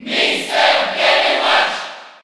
Category:Mr. Game & Watch (SSB4) Category:Crowd cheers (SSB4) You cannot overwrite this file.
Mr._Game_&_Watch_Cheer_French_PAL_SSB4.ogg